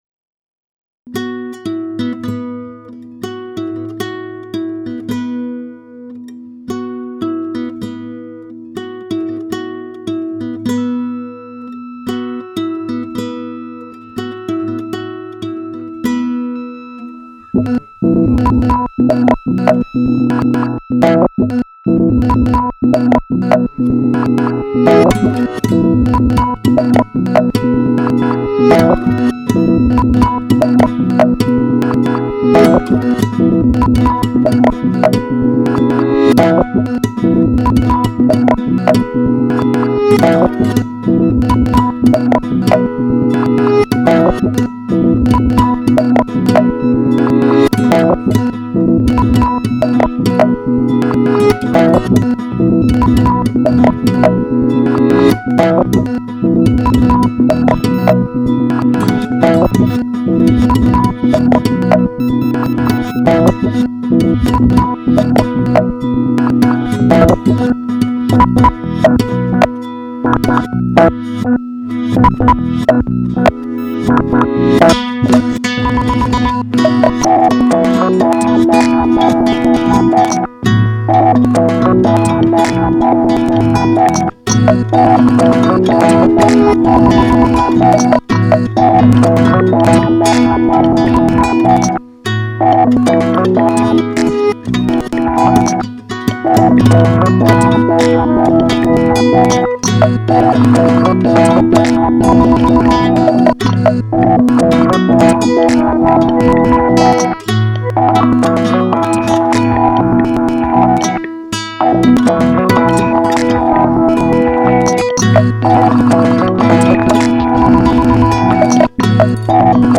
electronic music
Italian duo